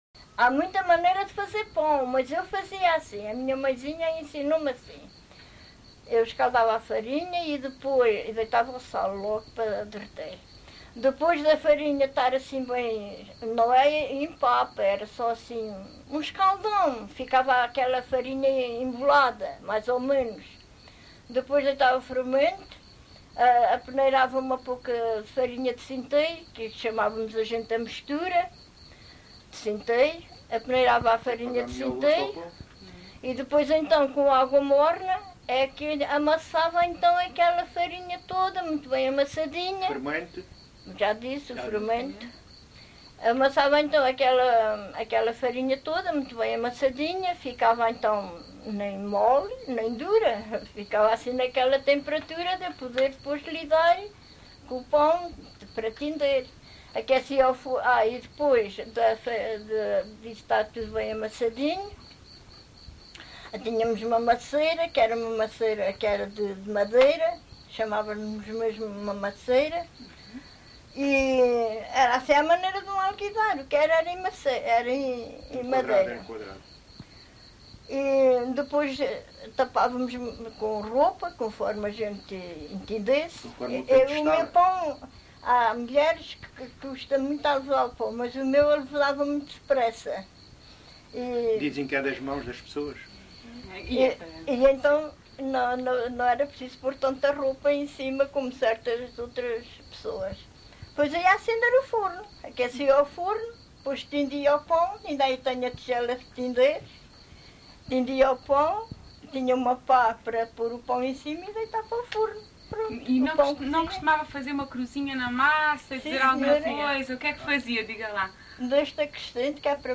LocalidadeMontalvo (Constância, Santarém)